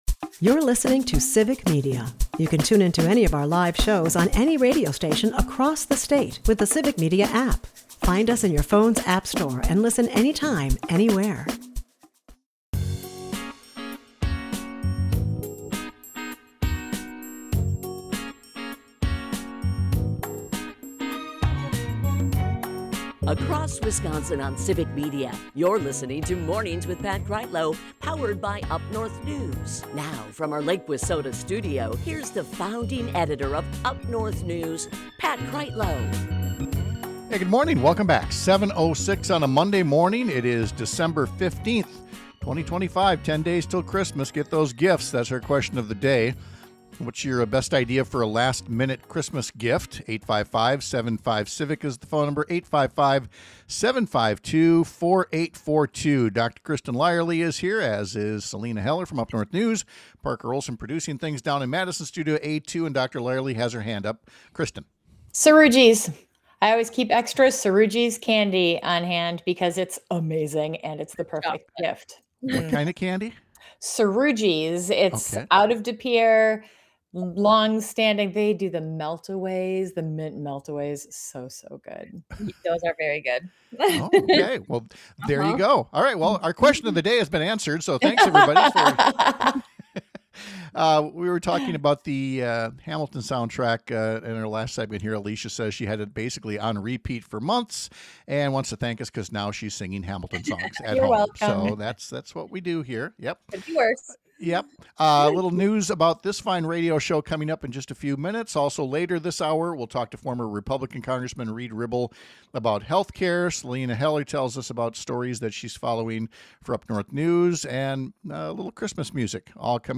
Former GOP Congressman Reid Ribble joins us to talk about the lack of a serious Republican healthcare plan — something he says shouldn’t be the case. We’ll hear his suggestions to his old colleagues.